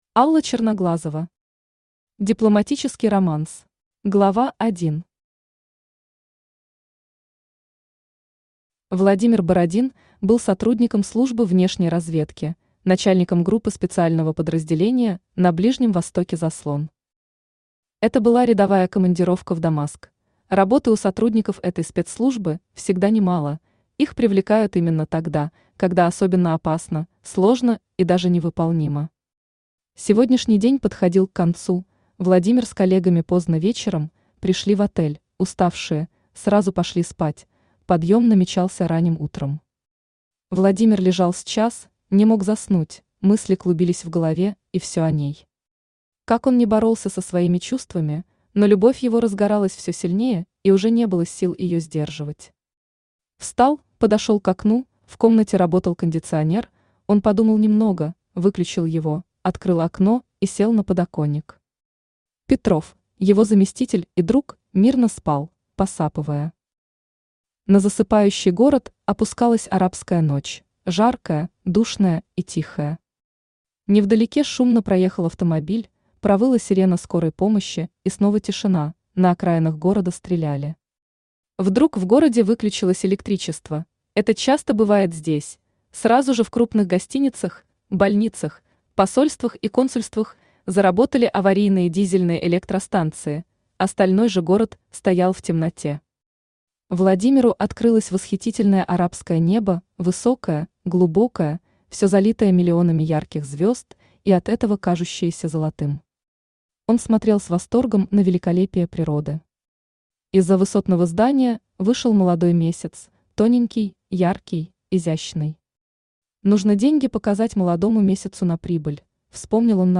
Aудиокнига Дипломатический романс Автор Алла Черноглазова Читает аудиокнигу Авточтец ЛитРес.